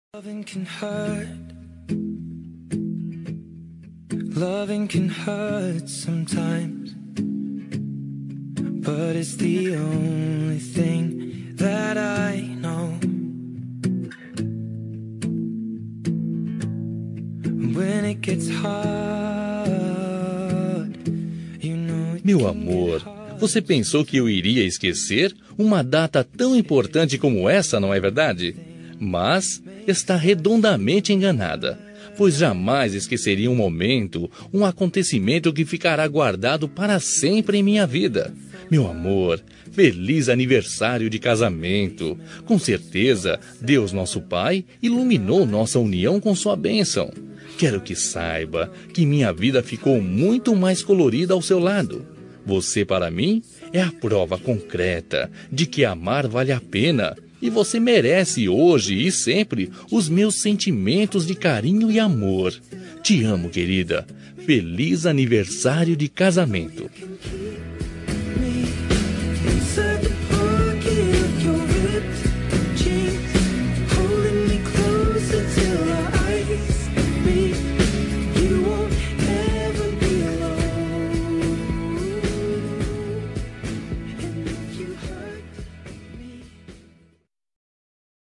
Telemensagem de Aniversário de Casamento Romântico – Voz Masculina – Cód: 4234